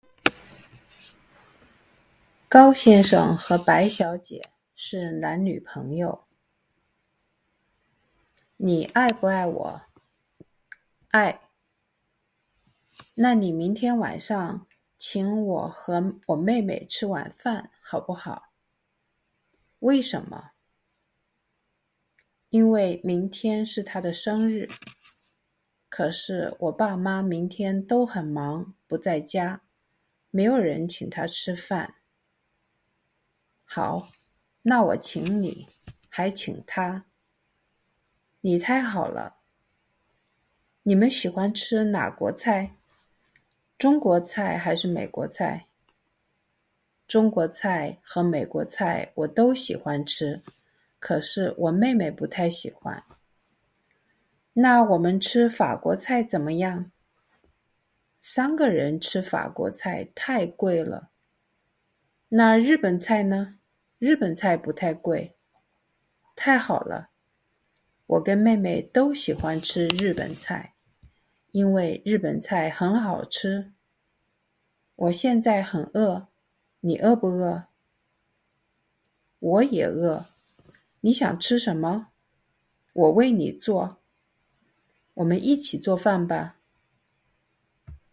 Dialogue:
Slow-speed reading: